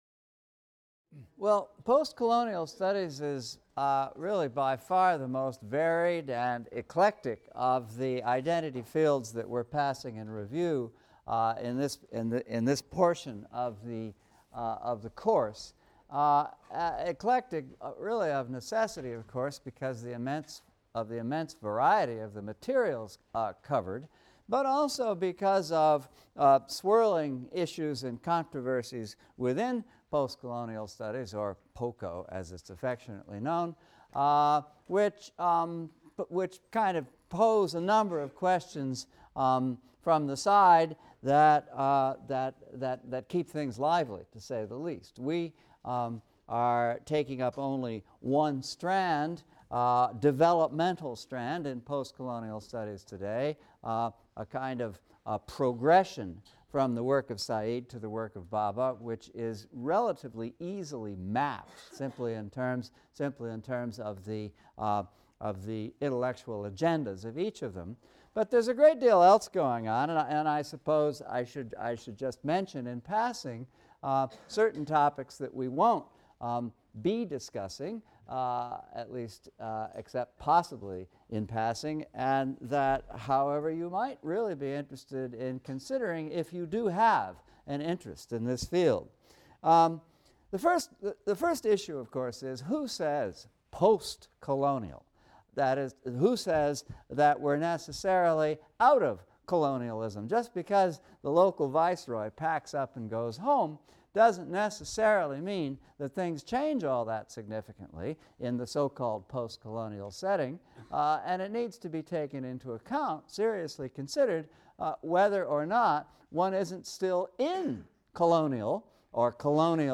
ENGL 300 - Lecture 22 - Post-Colonial Criticism | Open Yale Courses